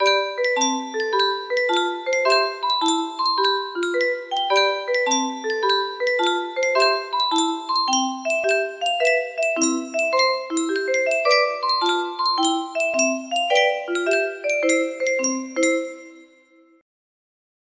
Yunsheng Music Box - Funiculi Funicula 248 music box melody
Full range 60